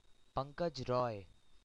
pronunciation; 31 May 1928 – 4 February 2001) was an Indian cricketer who played in 43 test matches, including once as captain.[1][2][3] He was a right-handed opening batsman, perhaps best known for establishing the world record opening partnership in Test cricket of 413 runs, together with Vinoo Mankad, against New Zealand at Chennai.